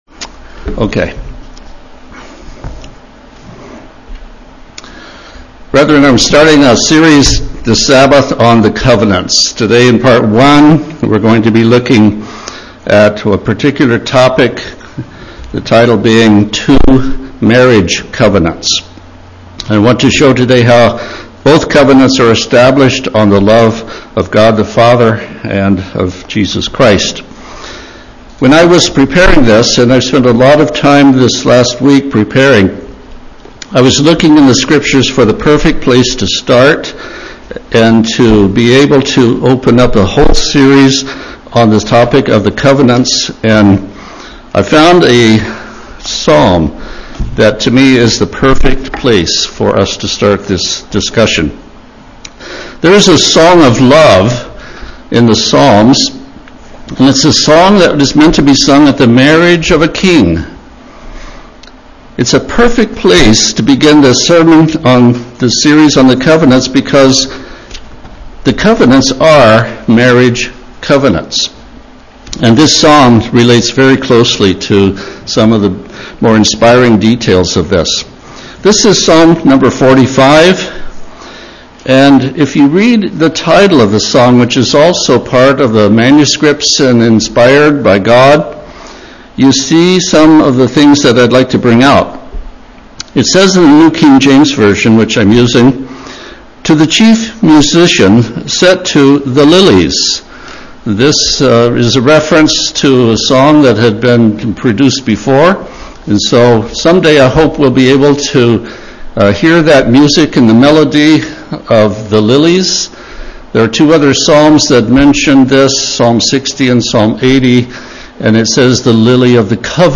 The message begins a series on the Covenants, comparing and contrasting the Old and the New Covenants, both of which are marriage covenants. In this first sermon in the series, it is the love of God the Father and Jesus Christ that we focus on, as the foundation upon which the covenants are established.